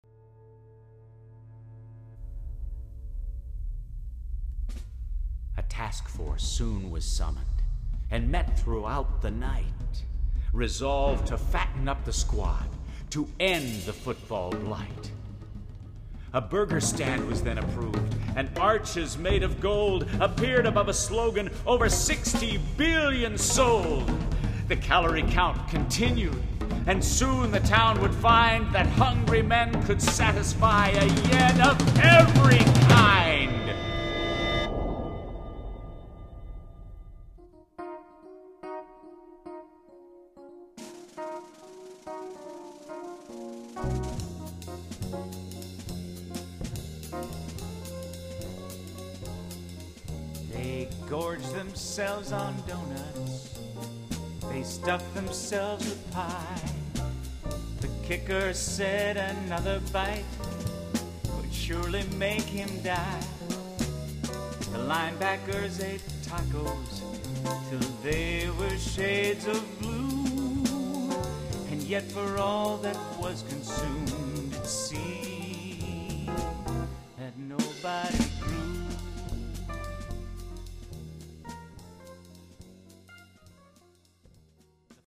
Please note: These samples are not of CD quality.
for Basses, Percussion and Tape